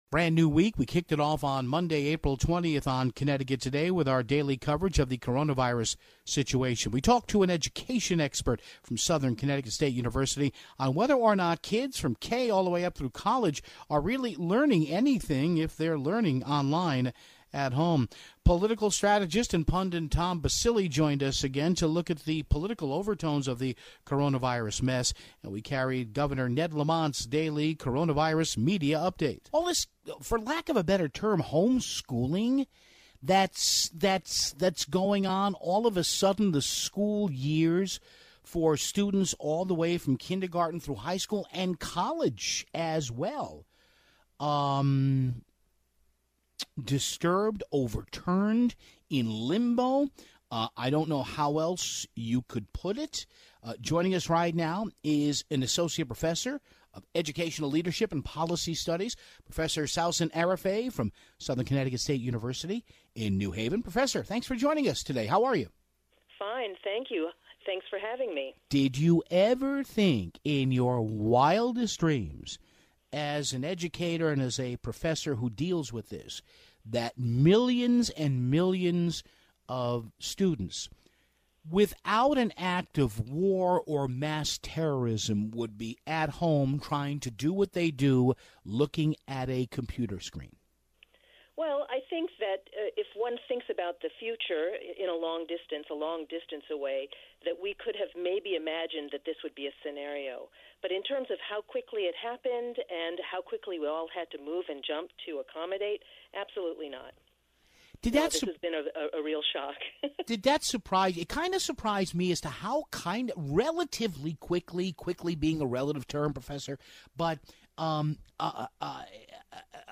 And, as always, our daily check-in with Governor Ned Lamont on the state's cases